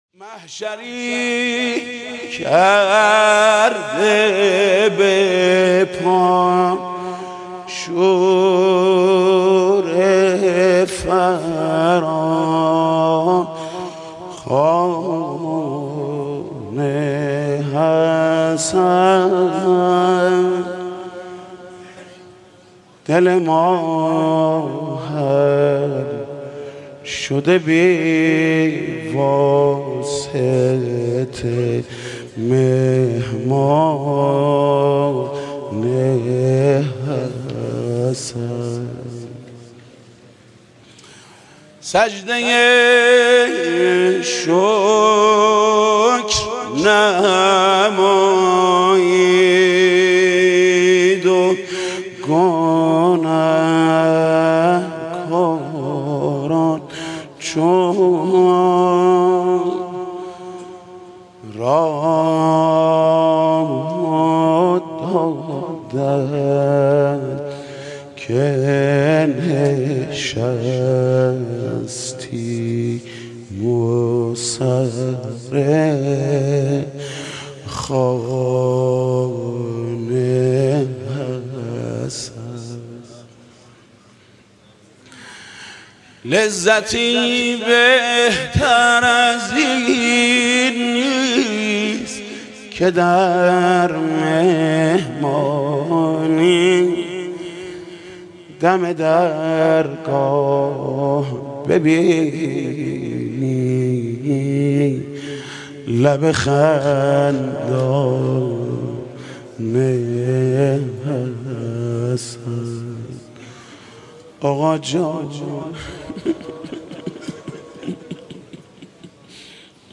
مدح: محشری کرده به پا